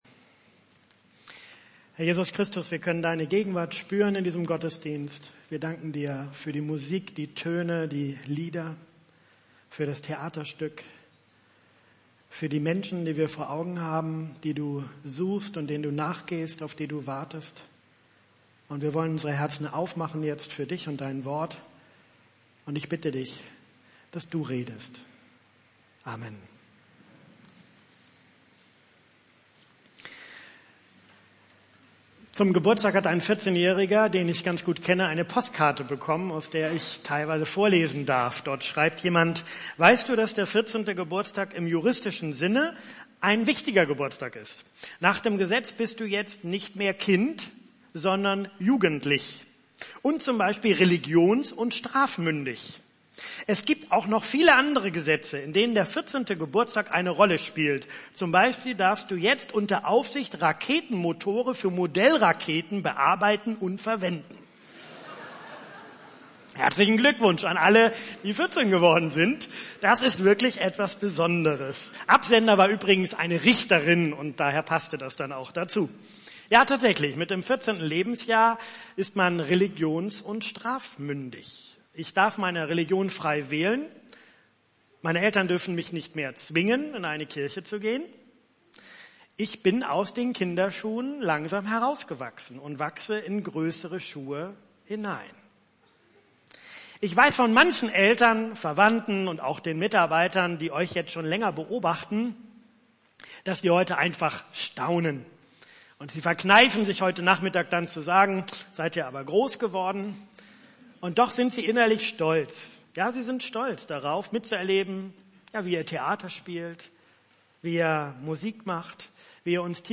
Segnungsgottesdienst für Jugendliche des GuLG